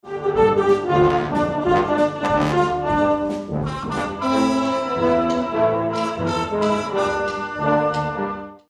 Full Brass Band arrangement